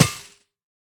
Minecraft Version Minecraft Version 25w18a Latest Release | Latest Snapshot 25w18a / assets / minecraft / sounds / block / spawner / break3.ogg Compare With Compare With Latest Release | Latest Snapshot
break3.ogg